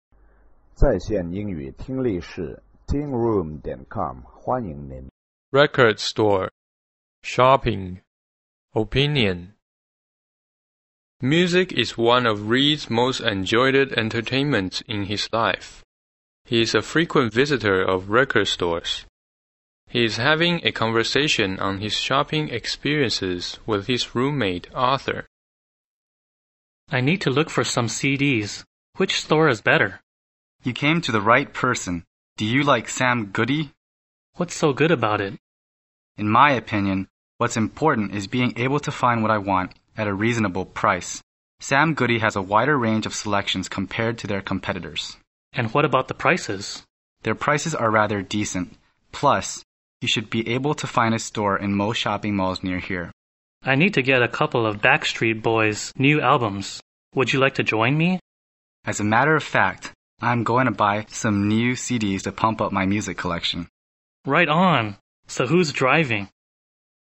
EPT美语 购物（对话） 听力文件下载—在线英语听力室